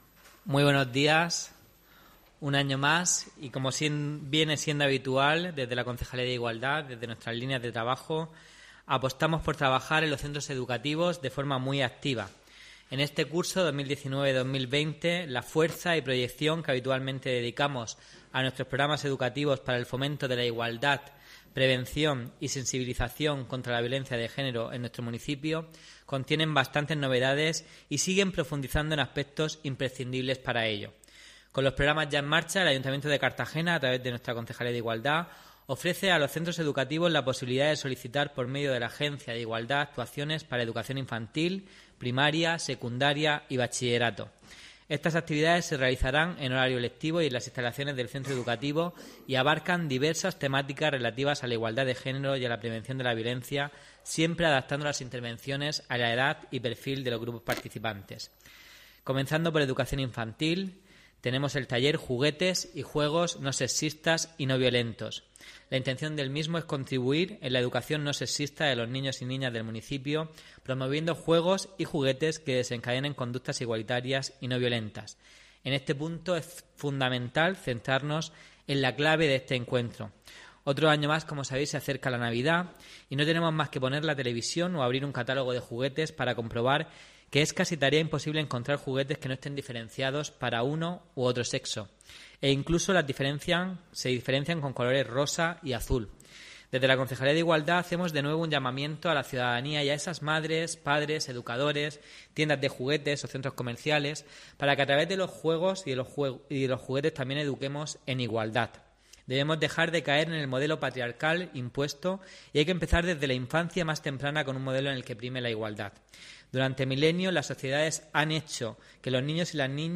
Audio: Presentaci�n Campa�a Igualdad 'Juguetes no sexistas' y programa educativo infantil (MP3 - 5,69 MB)